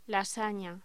Locución: Lasaña
voz